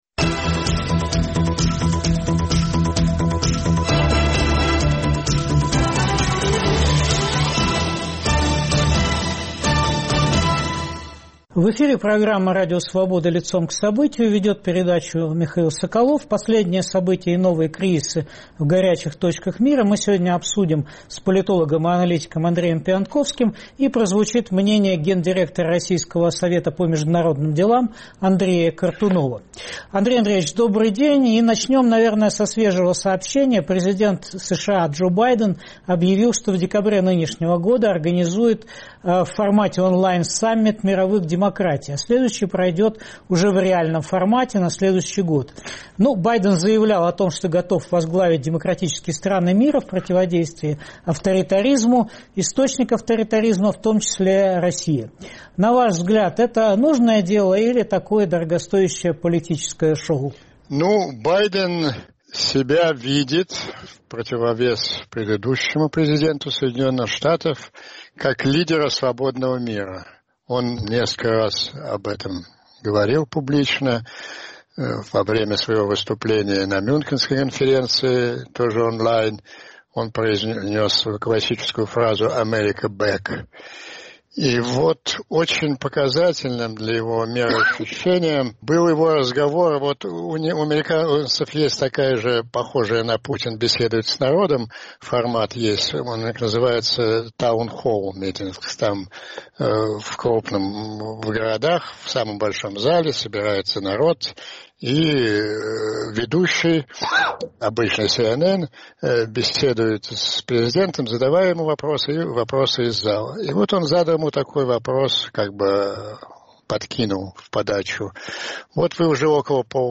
Обсуждаем с аналитиком и политологом Андреем Пионтковским. Участвует американист Андрей Кортунов.